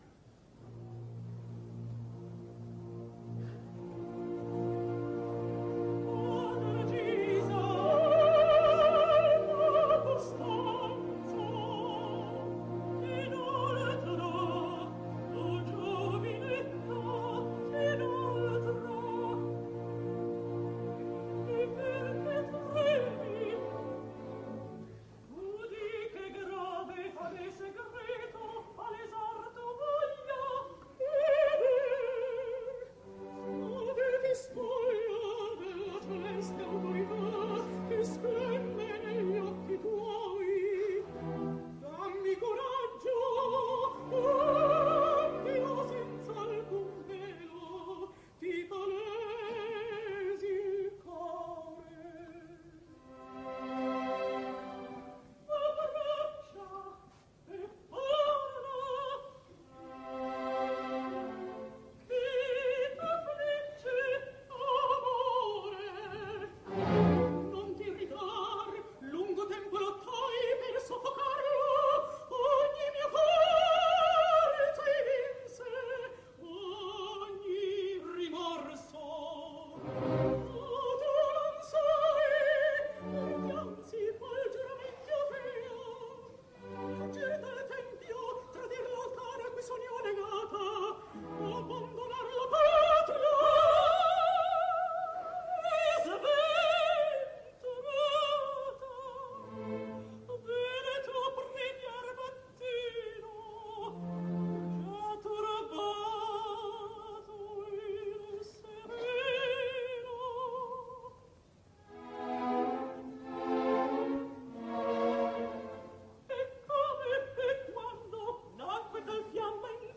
Edizione HRE del 1965, opera completa, registrazione dal vivo.
Orchestra del Teatro alla Scala di Milanodiretta da Gianandrea Gavazzeni.